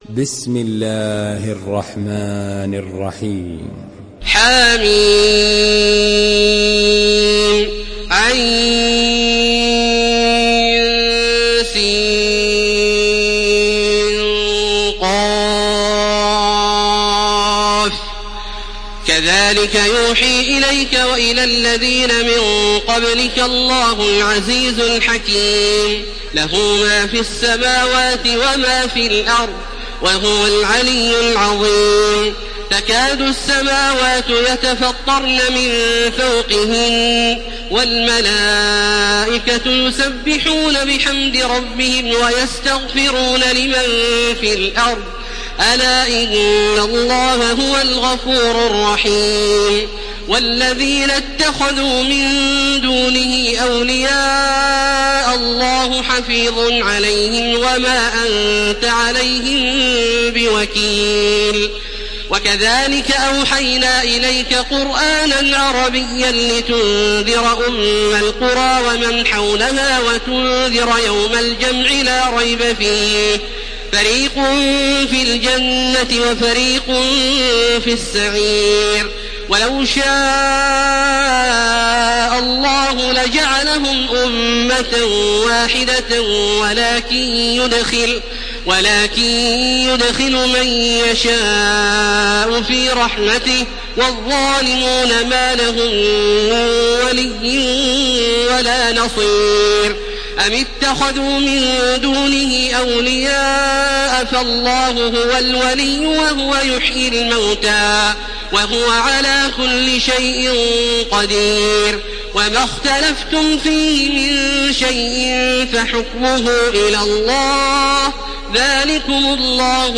Surah الشورى MP3 in the Voice of تراويح الحرم المكي 1431 in حفص Narration
Listen and download the full recitation in MP3 format via direct and fast links in multiple qualities to your mobile phone.
مرتل